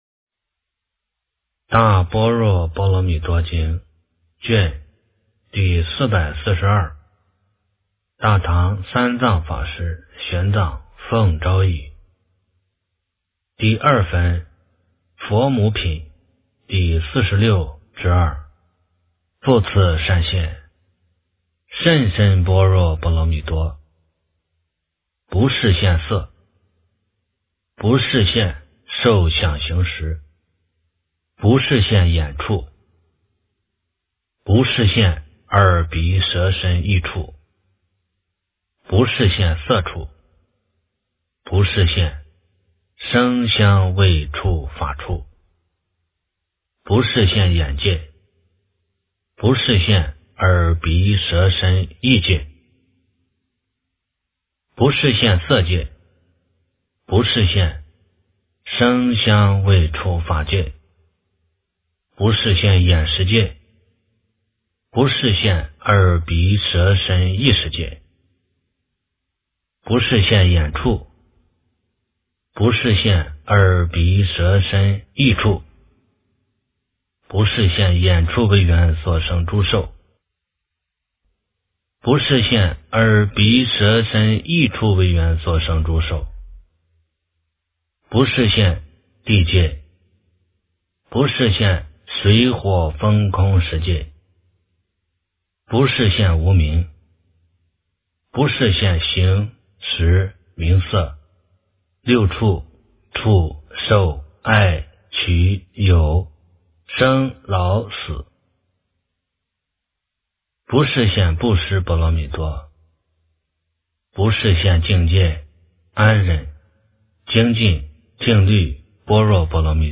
大般若波罗蜜多经第442卷 - 诵经 - 云佛论坛